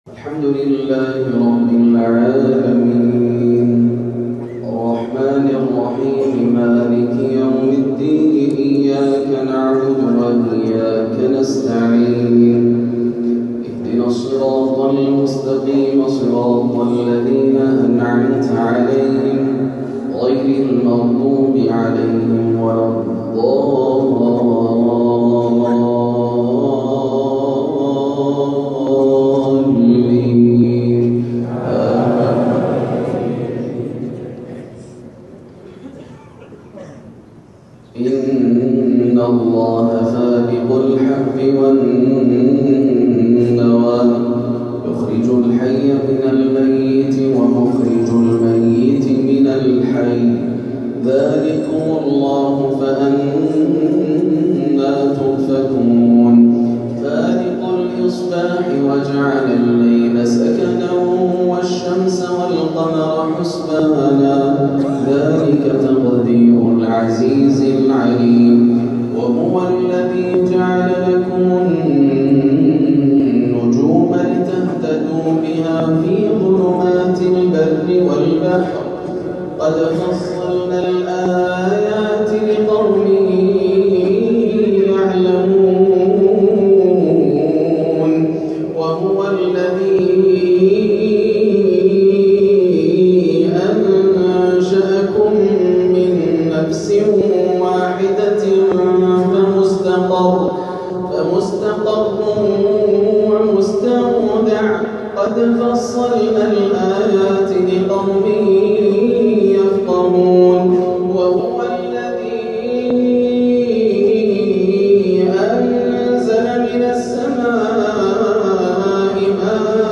عشاء الاحد 6-8-1439هـ من جامع الراجحي بحفر الباطن من سورتي الأنعام 95-104 و الحشر 18-24 > عام 1439 > الفروض - تلاوات ياسر الدوسري